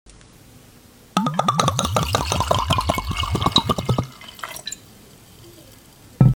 Soothing Whiskey Pour Sound
Soothing-Whiskey-Pour-Sound.mp3